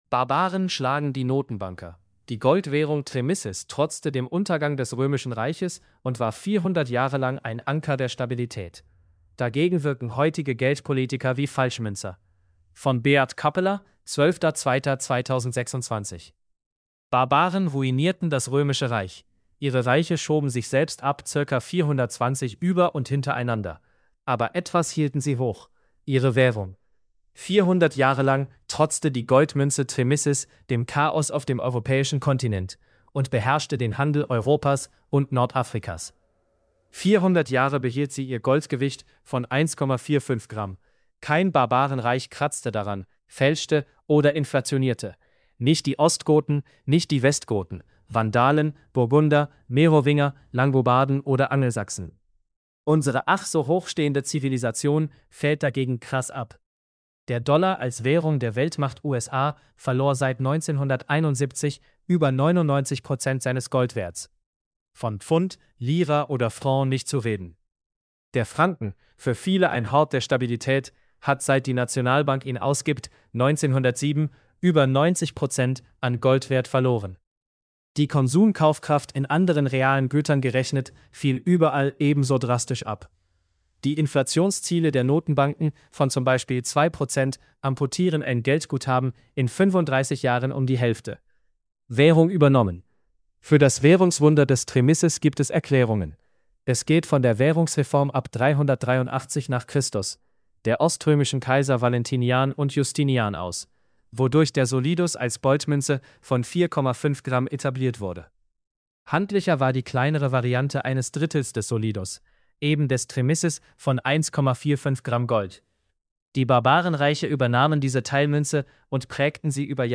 output_male_cedar.mp3